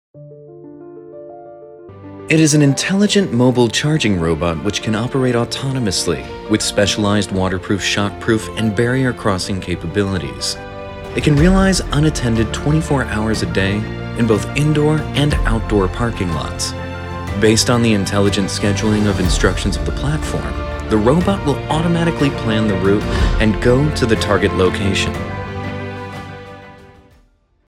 特点：大气浑厚 稳重磁性 激情力度 成熟厚重
风格:浑厚配音
134外籍美式男声解说.mp3